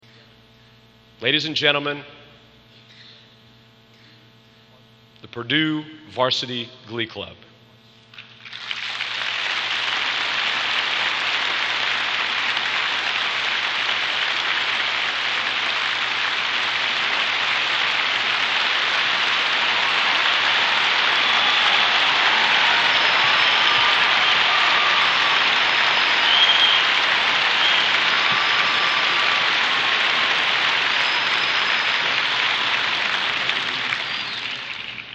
Collection: Centennial Celebration Concert 1993
Genre: | Type: Director intros, emceeing